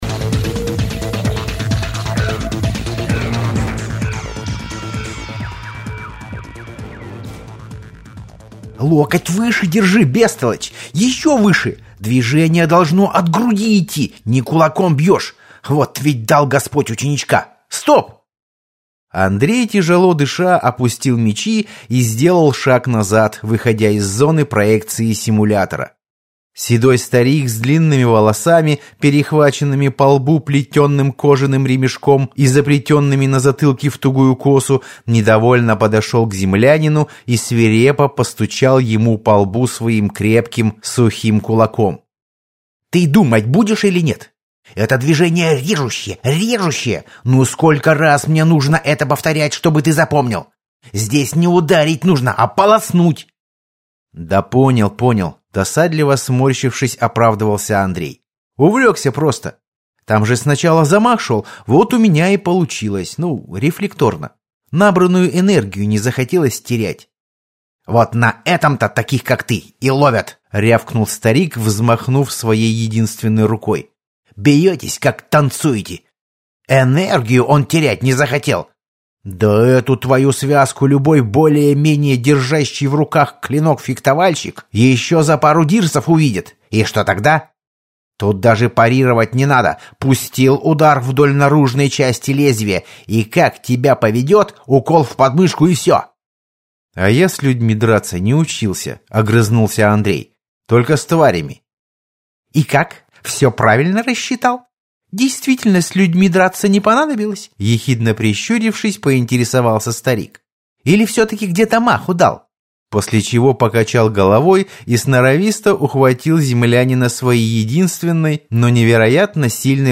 Аудиокнига Ком. В глубину - купить, скачать и слушать онлайн | КнигоПоиск